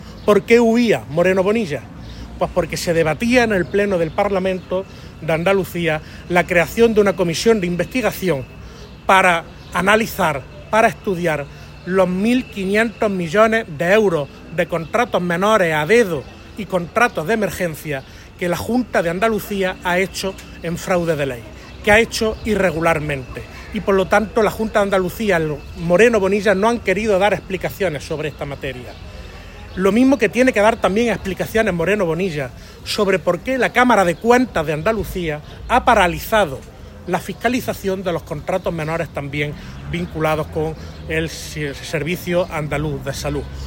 El parlamentario socialista Víctor Torres exigió hoy al presidente de la Junta de Andalucía que “no huya” y que dé explicaciones en el Parlamento sobre los 1.500 millones de euros en contratos menores a dedo y en contratos de emergencia que la Junta ha hecho irregularmente en fraude de ley.
Cortes de sonido # Víctor Torres